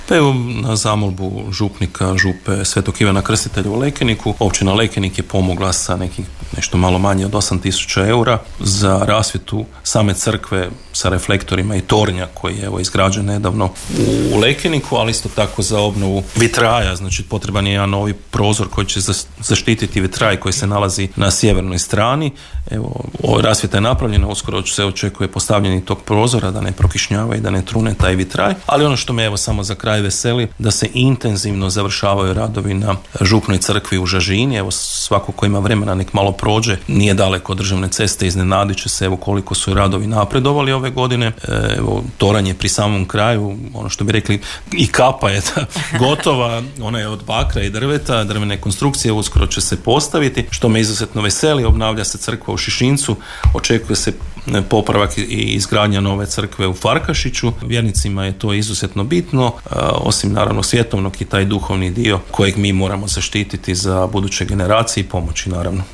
Nedavno se na to osvrnuo načelnik Ivica Perović